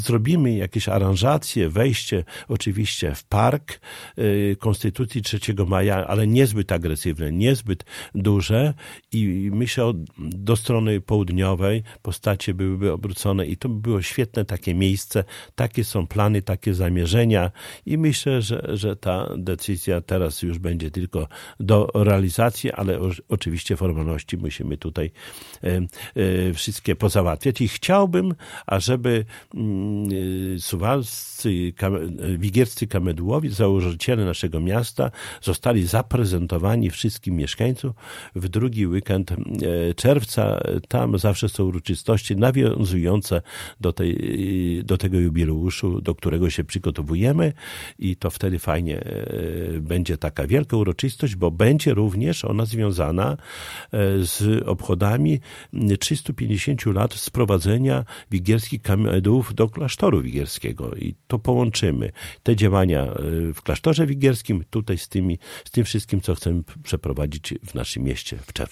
Szczegóły przedstawił w piątek (13.10) w Radiu 5 Czesław Renkiewicz, prezydent Suwałk.